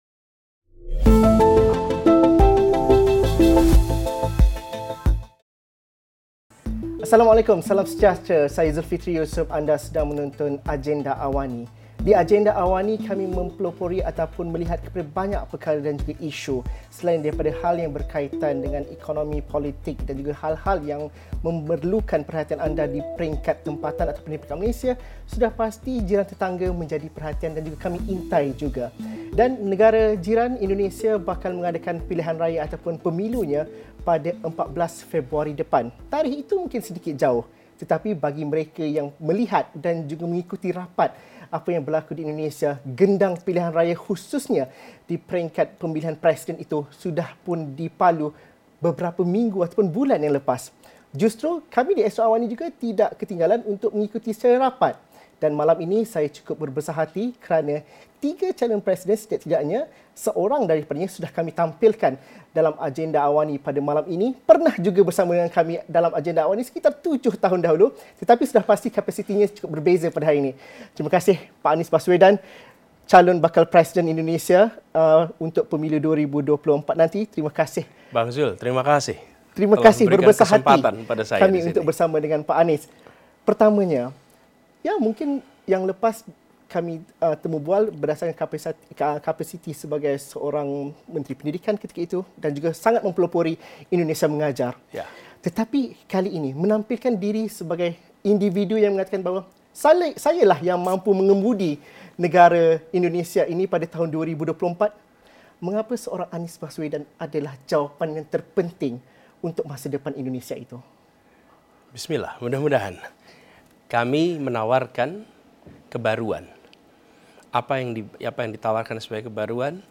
Headliner Embed Embed code See more options Share Facebook X Subscribe Menjelang Pemilihan Umum Presiden Indonesia 2024, apa perjuangan politik baharu yang ingin dibawa oleh bakal calon-calon khususnya terkait isu ekonomi, pendidikan dan hubungan antarabangsa? Temu bual bersama bakal calon Presiden yang juga Bekas Gabenor Jakarta, Dr. Anies Baswedan 9 malam ini.